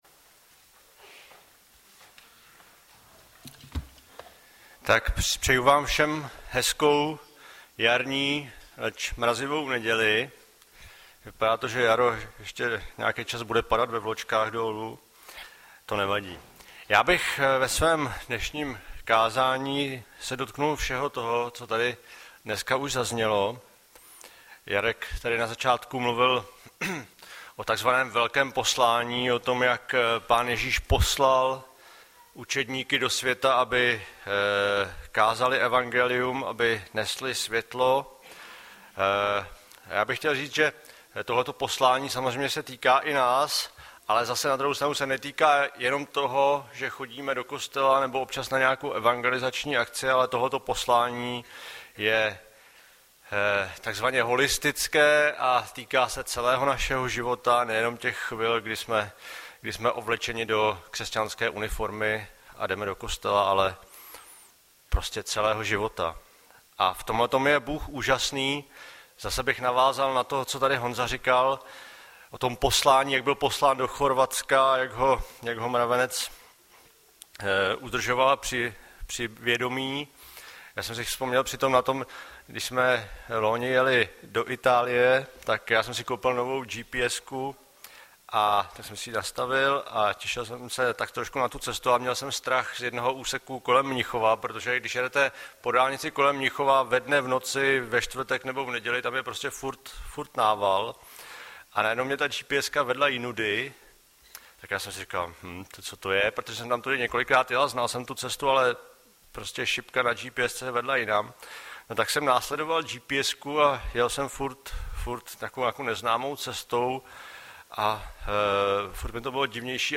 Hlavní nabídka Kázání Chvály Kalendář Knihovna Kontakt Pro přihlášené O nás Partneři Zpravodaj Přihlásit se Zavřít Jméno Heslo Pamatuj si mě  07.04.2013 - BOŽÍ OBRAZ V NÁS - Gen 1,27 Audiozáznam kázání si můžete také uložit do PC na tomto odkazu.